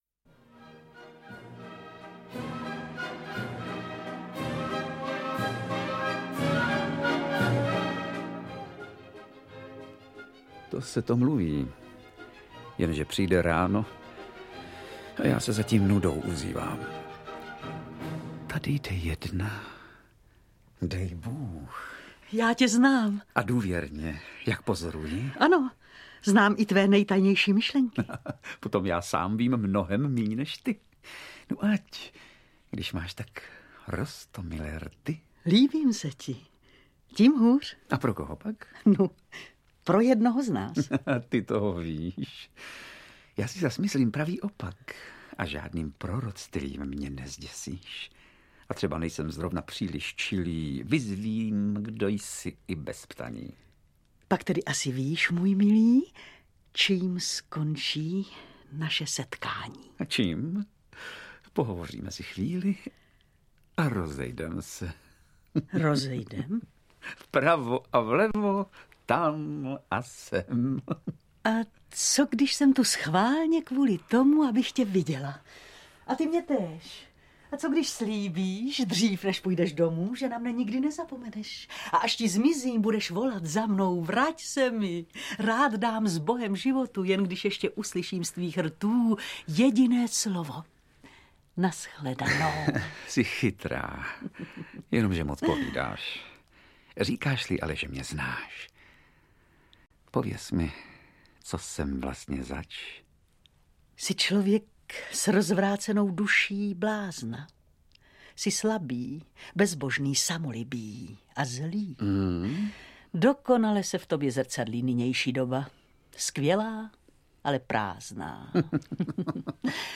AudioKniha ke stažení, 5 x mp3, délka 54 min., velikost 49,2 MB, česky